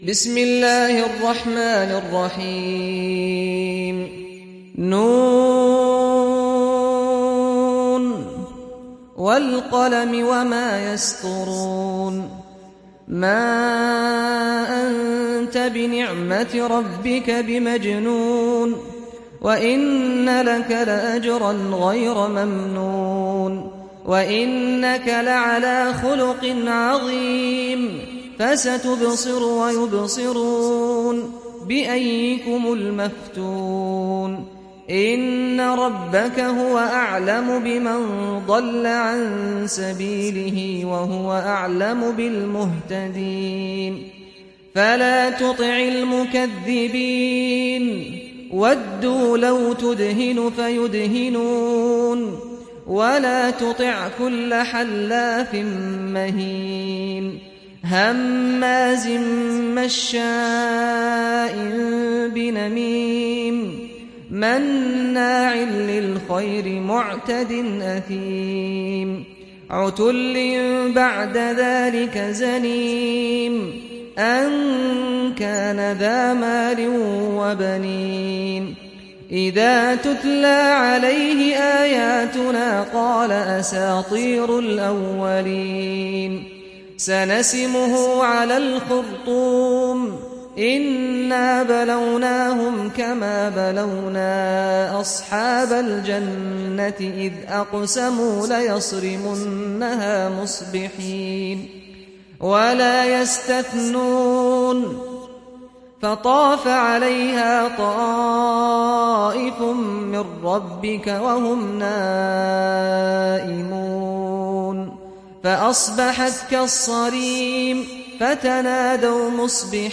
Sourate Al Qalam Télécharger mp3 Saad Al-Ghamdi Riwayat Hafs an Assim, Téléchargez le Coran et écoutez les liens directs complets mp3